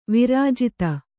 ♪ virājita